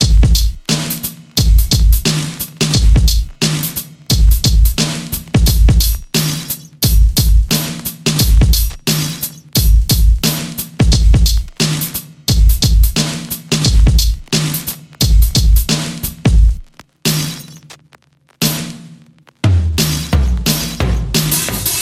Clean Hip Drums
描述：lean hip drums
标签： 88 bpm Hip Hop Loops Drum Loops 1.84 MB wav Key : Unknown
声道立体声